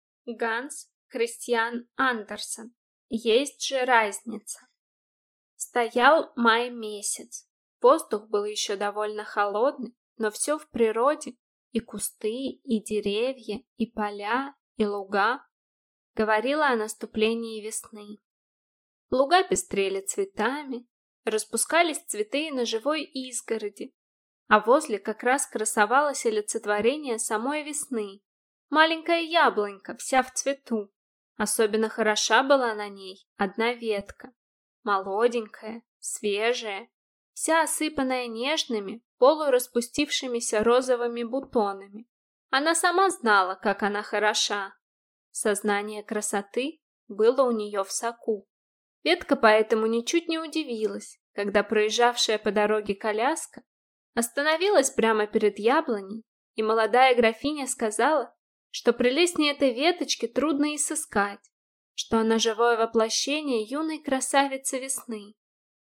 Аудиокнига «Есть же разница!»